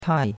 tai4.mp3